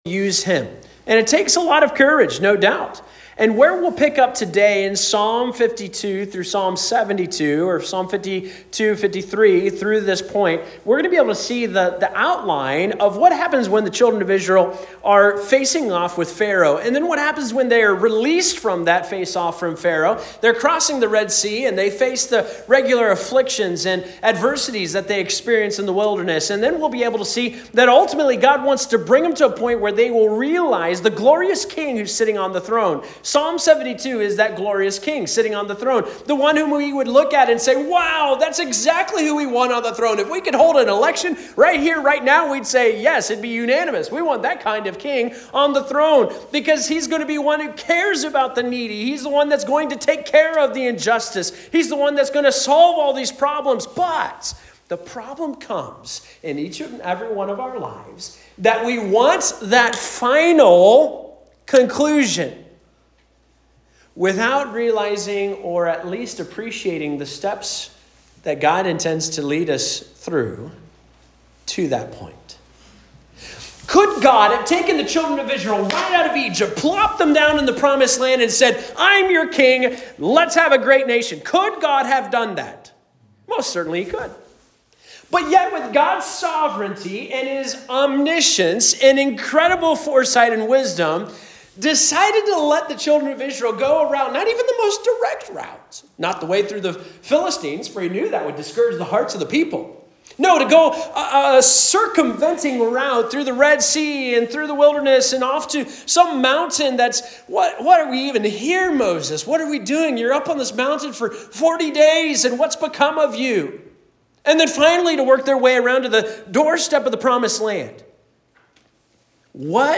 Sermons | Anthony Baptist Church